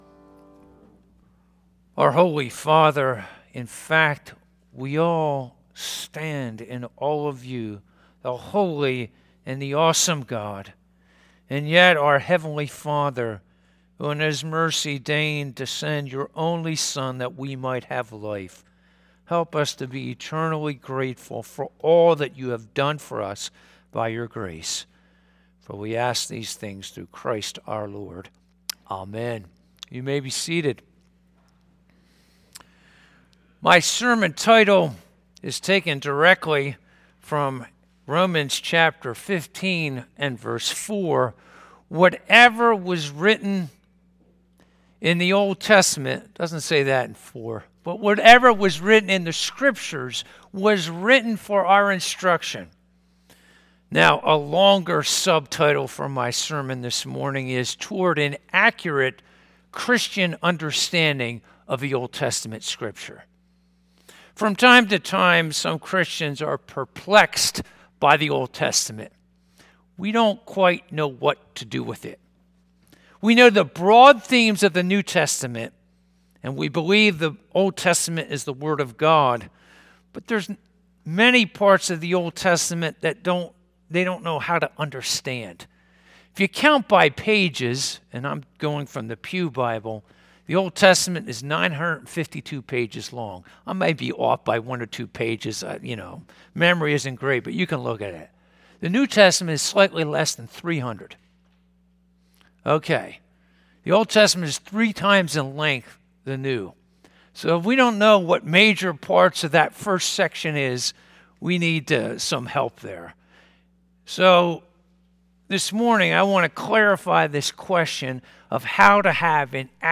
Romans 15:4-13 Service Type: Sunday Morning « What are the signs of Advent?